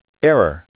הוא יוצר קובץ שמע שלפי מה שהבנתי ה-"אררררר" זה שגיאה